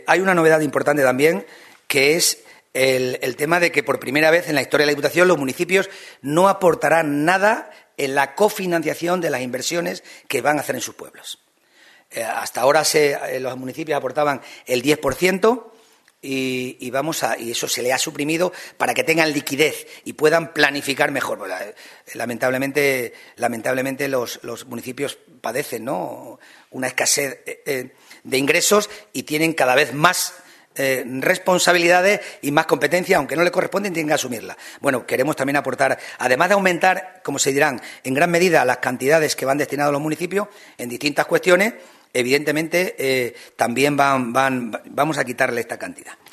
CORTES DE VOZ
Miguel Ángel Morales sánchez_ Presidente de la Diputación de Cáceres_Cofinanciación Ayuntamientos